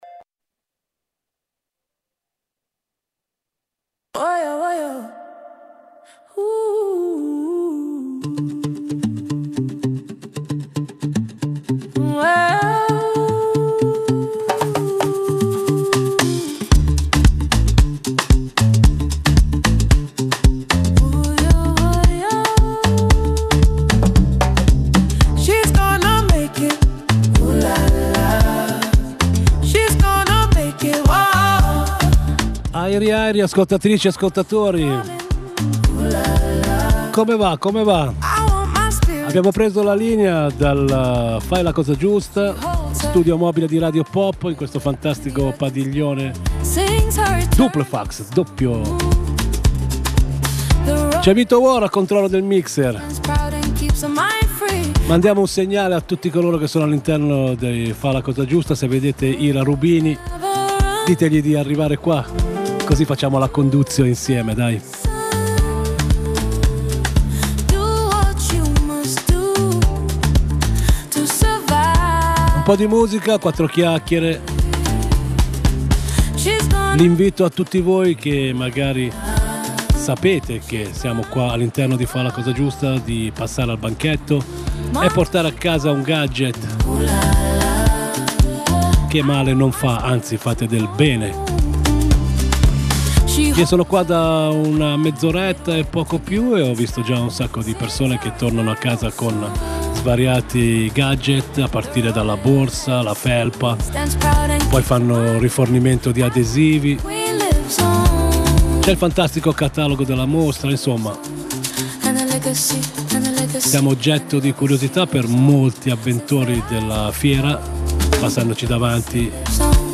Dal nostra stand a Rho Fiera, per Fa la cosa giusta.